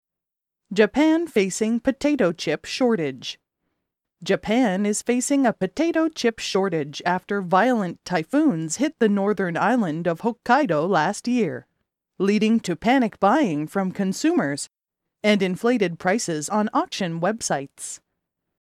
ナチュラルスピードで話される英語は、子音と母音の音がつながったり、子音が脱落して聞こえなくなる現象がよく起こります
※ここでは標準的なアメリカ英語のリスニングを想定しています。